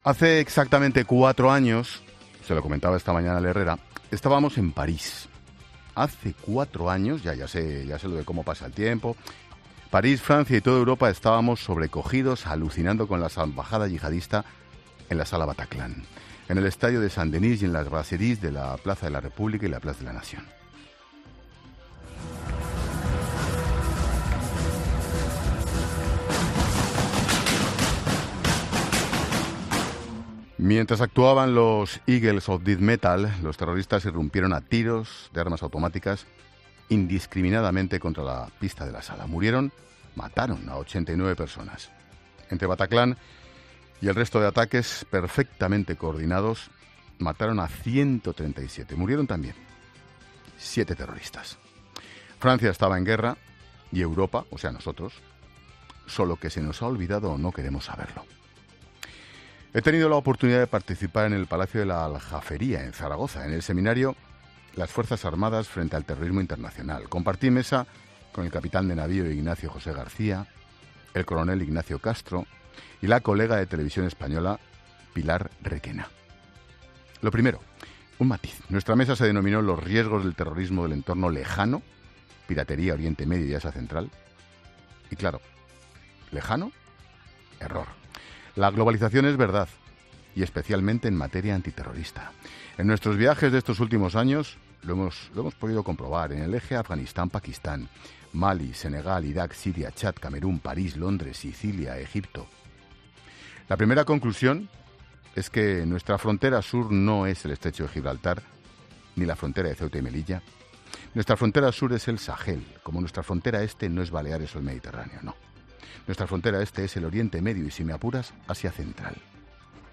Monólogo de Expósito
El presentador de la Linterna analiza la situación actual de terrorismo global tras los ataques en París, Berlín o Barcelona